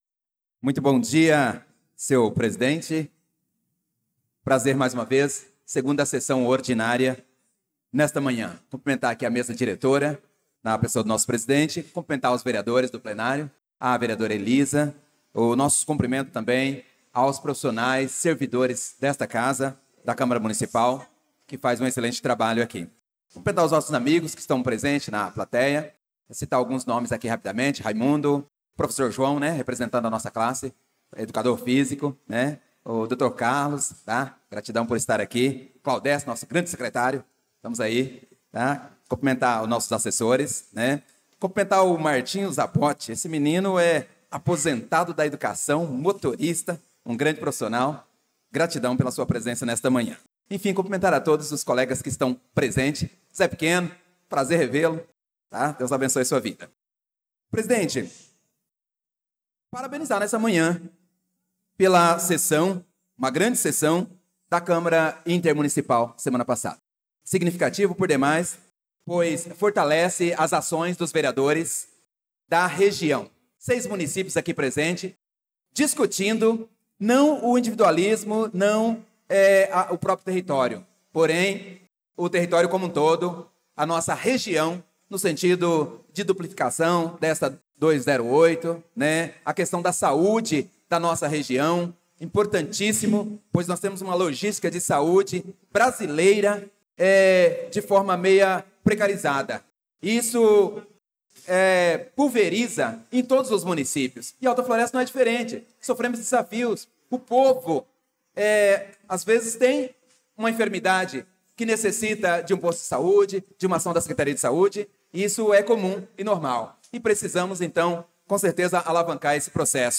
Pronunciamento do vereador Prof. Nilson na Sessão Ordinária do dia 11/02/2025